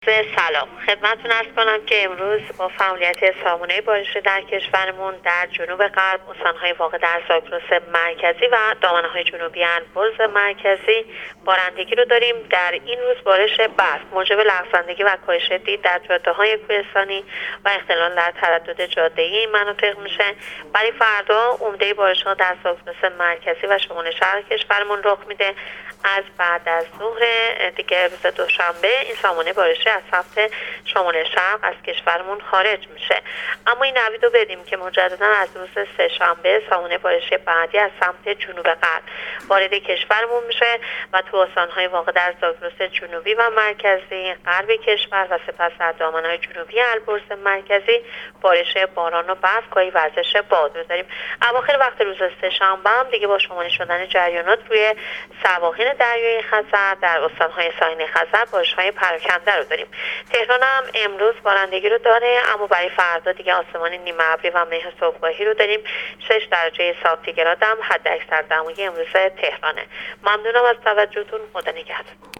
در گفتگو با راديو اينترنتی پايگاه خبری آخرين وضعيت هوا را تشريح كرد.